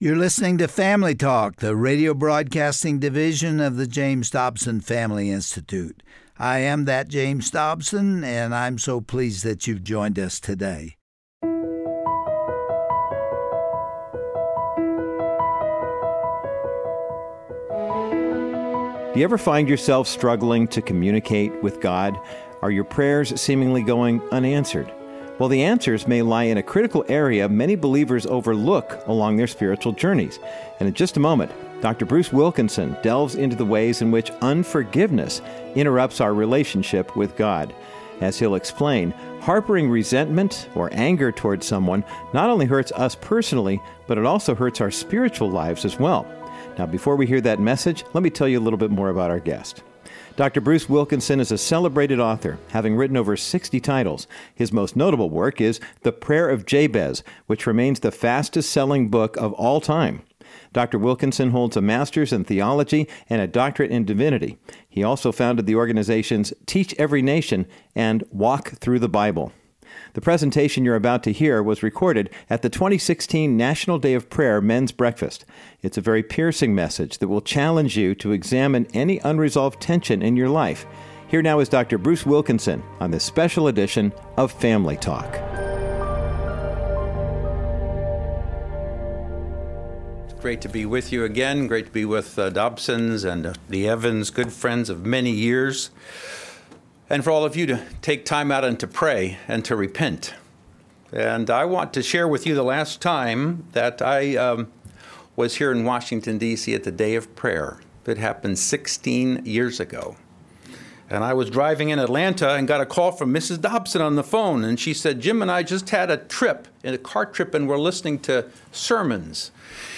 Dr. Bruce Wilkinson unpacks the ways unforgiveness can block our communication with our Heavenly Father. Through his message delivered at the National Day of Prayer in 2016, Dr. Wilkinson addresses the prevalent issue of unforgiveness in the church, and identifies what believers can do to restore their relationship with God.